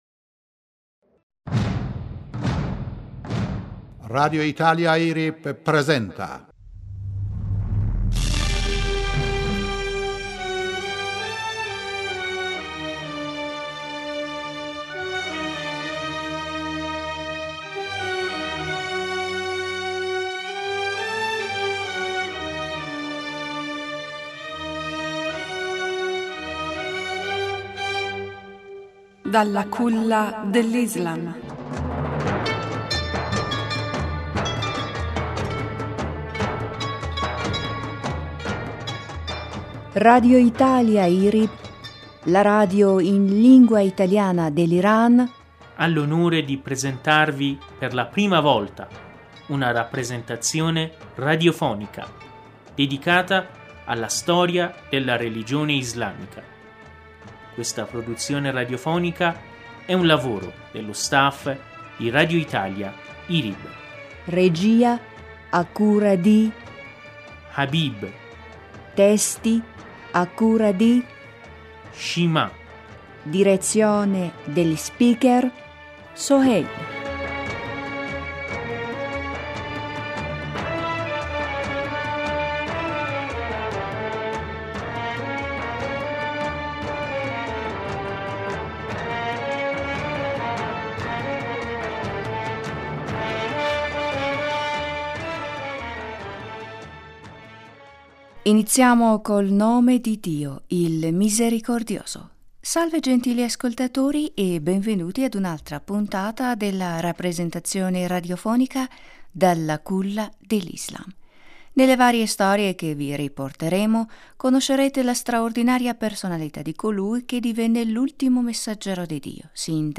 Salve gentili ascoltatori e beventui ad una altra puntata della rappresentazione radiofonica dalla “Culla dell...